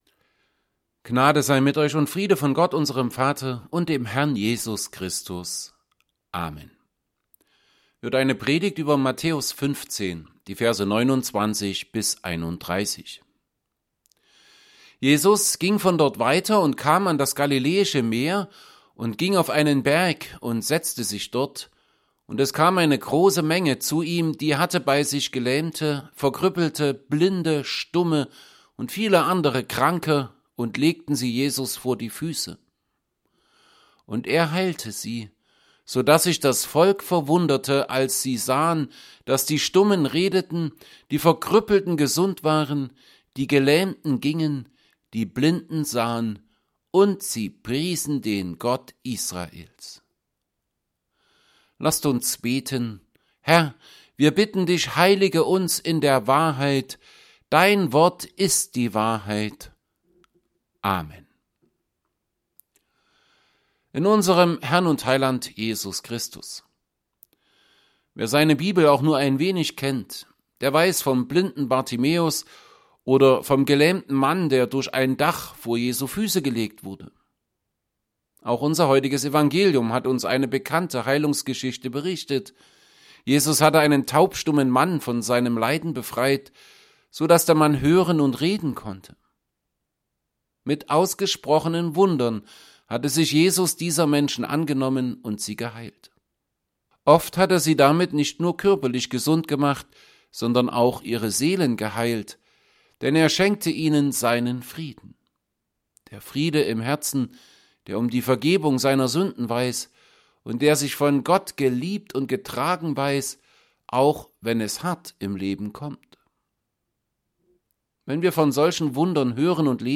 Evangelienpredigten Passage: Matthew 15:29-31 Gottesdienst: Gottesdienst %todo_render% Dateien zum Herunterladen Notizen « 11.